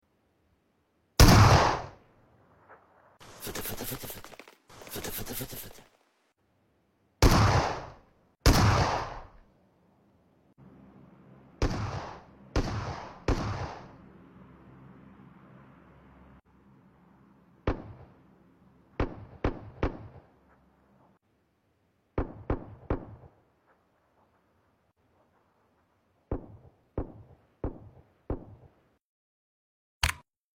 Pubg mobile S12k 🔊 10m sound effects free download